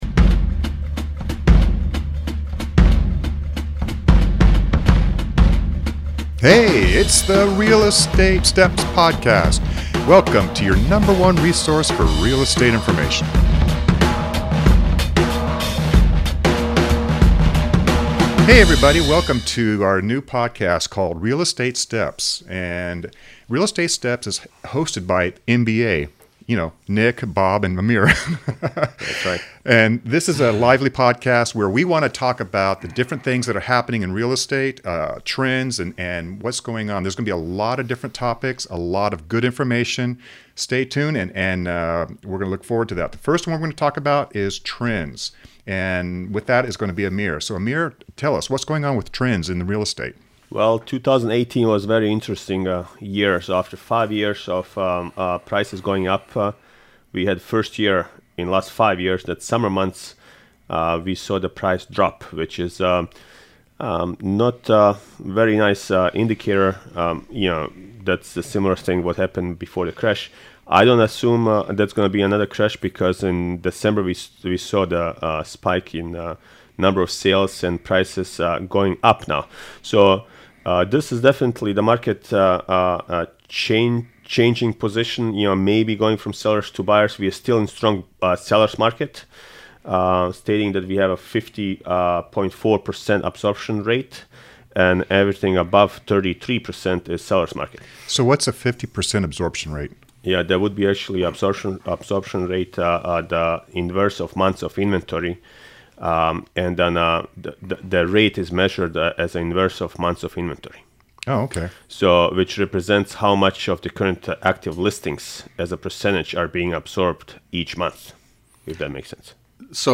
a lively and relevant weekly conversation on what's happening in the Real Estate market in the Sacramento region. It's full of tips, content, and advice for buyers, sellers, and real estate professionals drawn on the years of experience of 3 active Realtors.